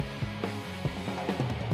＜わかりやすいようにEQで加工した音源＞
問題の声は「4拍目少し前に入っている「ハイ！」という声」
前後は何も声が入っていないので、ここだけピンポイントに入っているんですよね。。